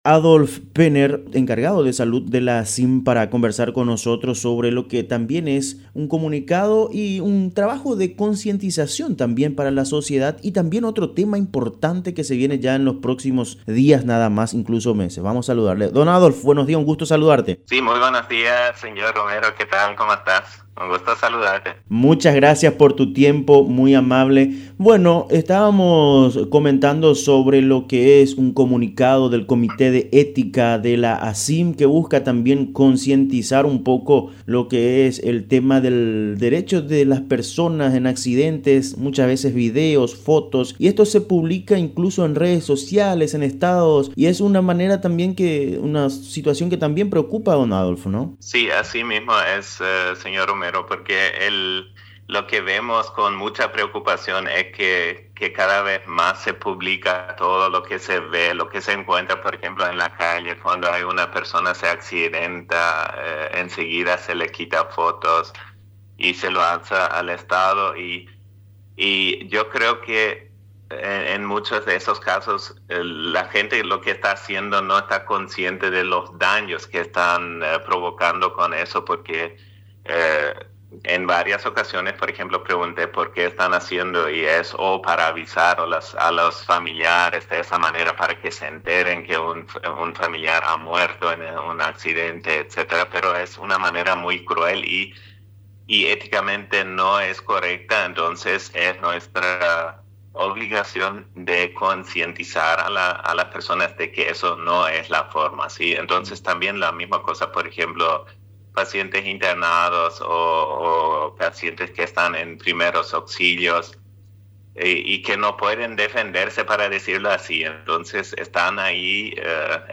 Entrevistas / Matinal 610 Respeto a la dignidad del paciente 11/03/2026 Mar 11 2026 | 00:11:42 1x 00:00 / 00:11:42 Subscribe Share RSS Feed Share Link Embed